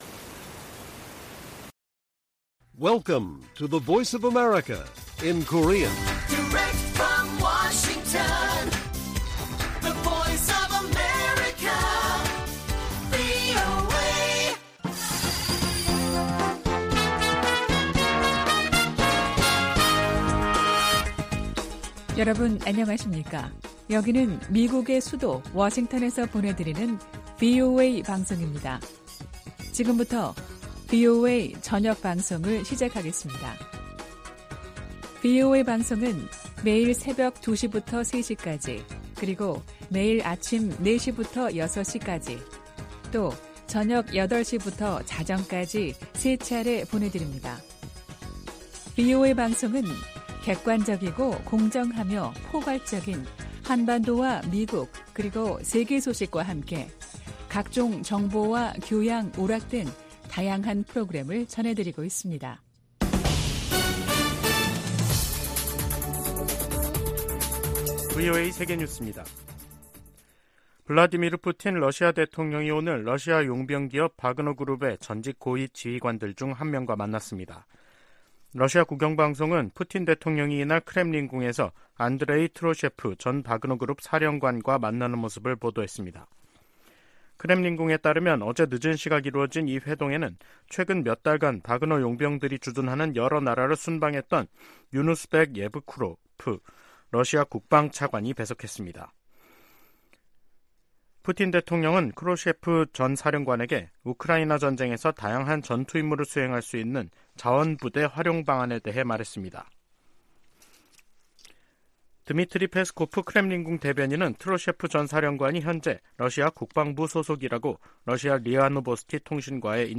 VOA 한국어 간판 뉴스 프로그램 '뉴스 투데이', 2023년 9월 29일 1부 방송입니다. 미 국무부는 북한의 핵무력 정책 헌법화를 비판하고, 평화의 실행 가능 경로는 외교뿐이라고 지적했습니다. 미 국방부는 북한이 전쟁의 어떤 단계에서도 핵무기를 사용할 수 있으며, 수천 톤에 달하는 화학 물질도 보유하고 있다고 밝혔습니다.